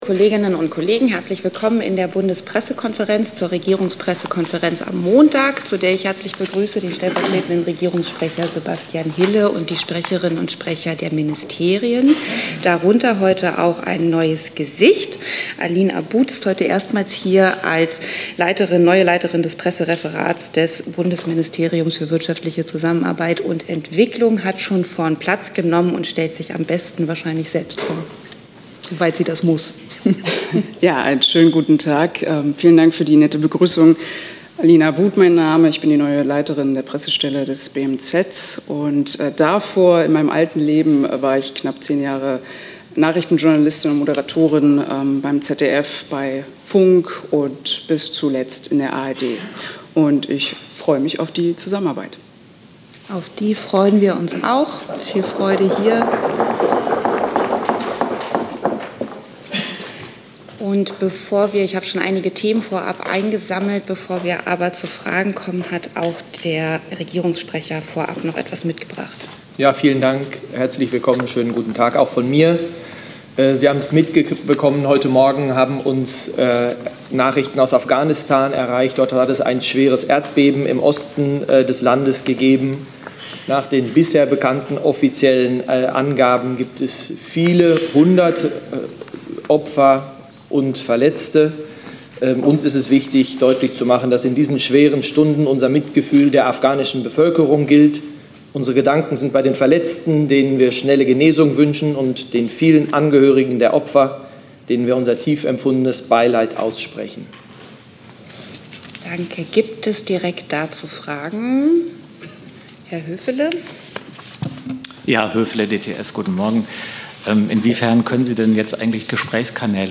Bundesregierung für Desinteressierte Regierungspressekonferenz in der BPK vom 27.